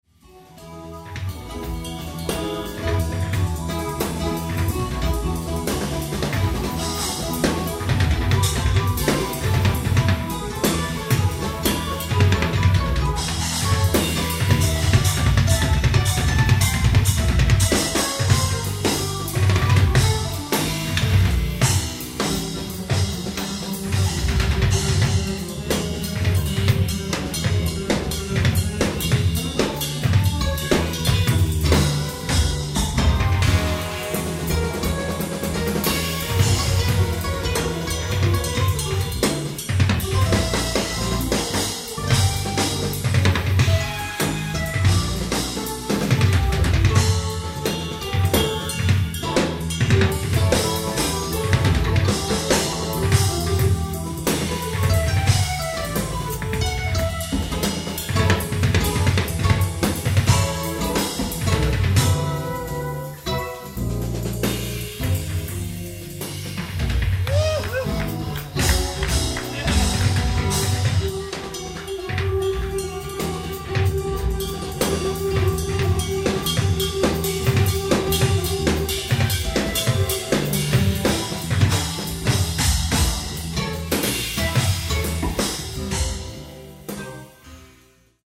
ライブ・アット・ボトムライン・クラブ、ニューヨーク、NY 07/03/1991(late show)
※試聴用に実際より音質を落としています。